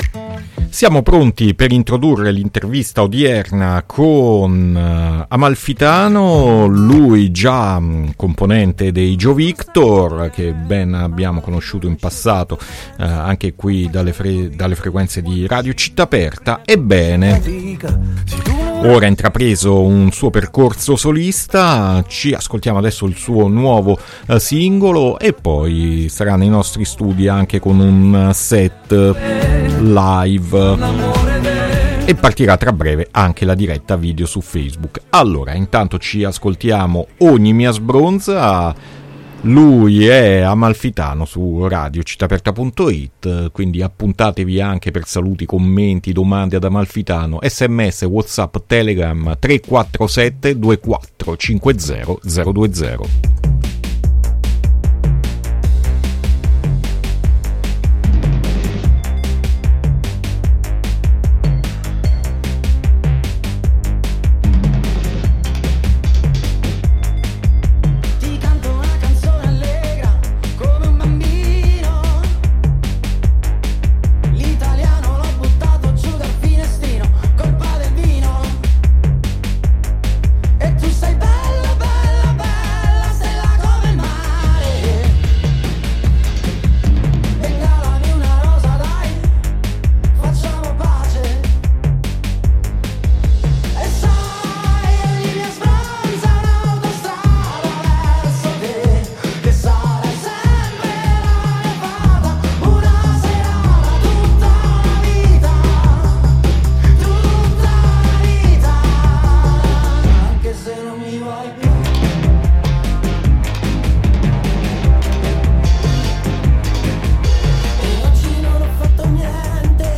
set acustico in radio